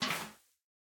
empty_powder_snow1.ogg